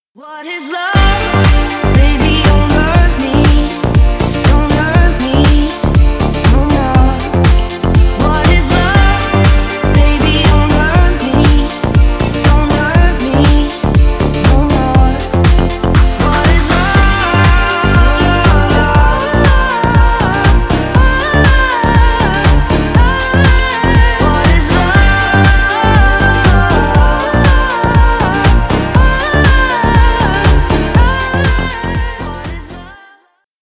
Скачать бесплатно на звонок